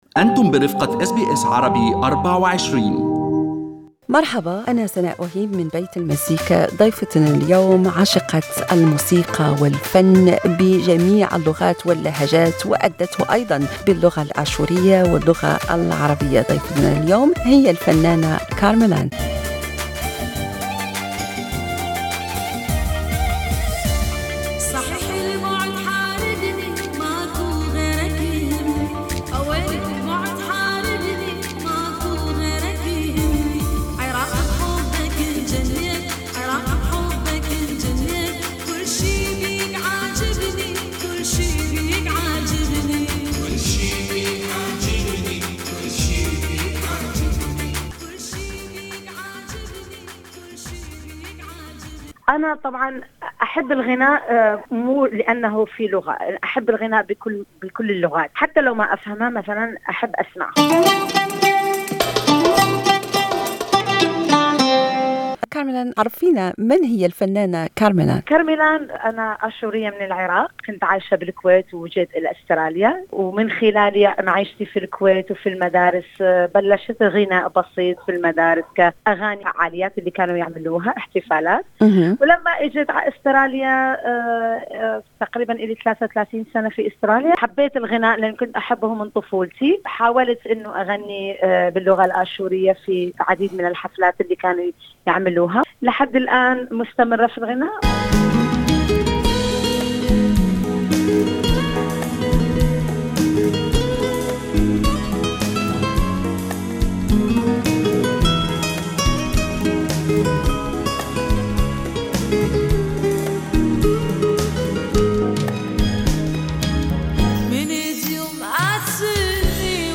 يستضيف برنامج بيت المزيكا في حلقته الأسبوعية الفنانة